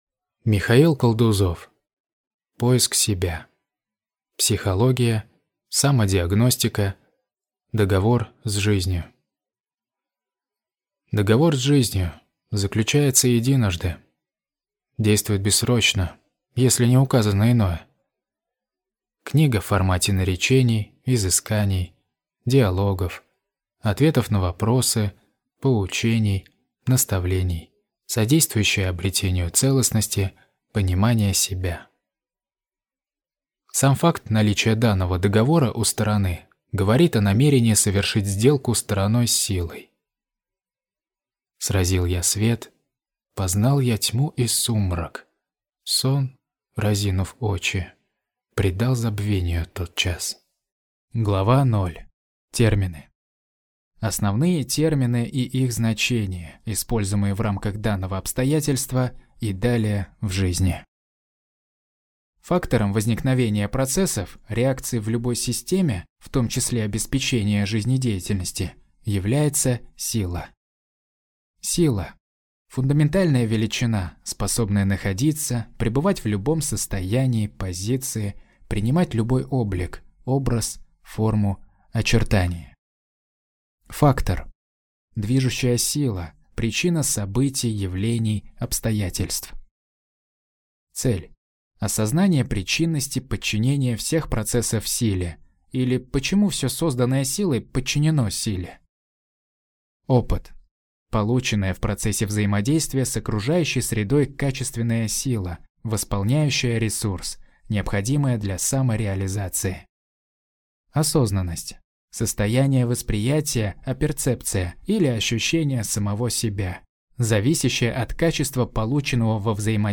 Аудиокнига Поиск себя. Психология. Самодиагностика. Договор с жизнью | Библиотека аудиокниг